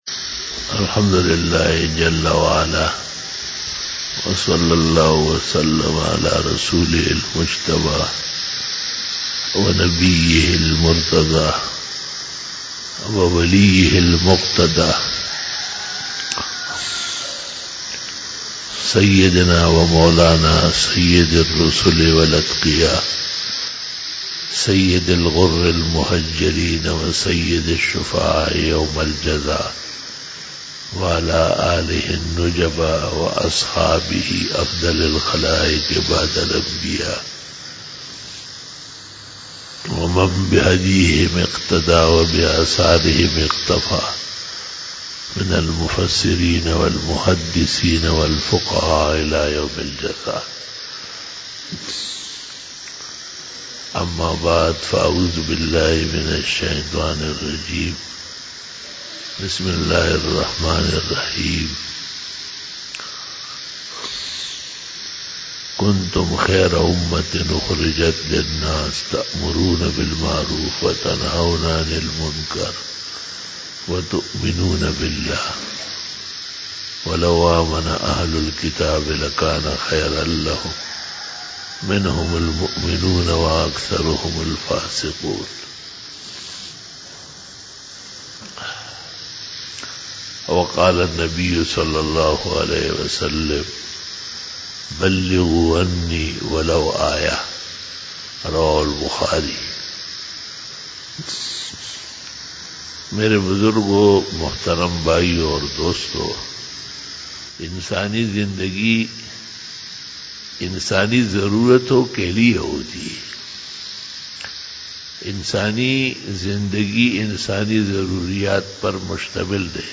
05 BAYAN E JUMA TUL MUBARAK 31 JANUARY 2020 (05 Jamadi Us Sani 1441H)